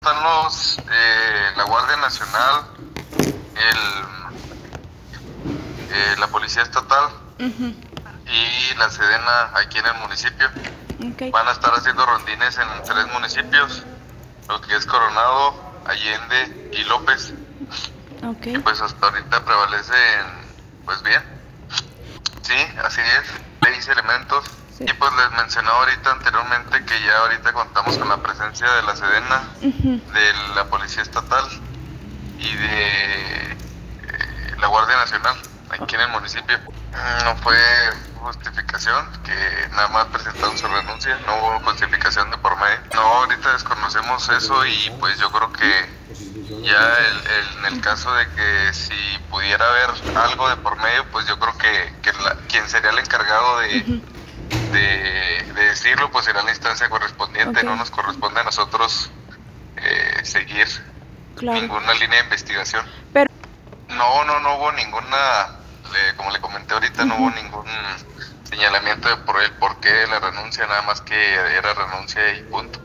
En el audio: Leo López Muñoz, alcalde de Villa Coronado.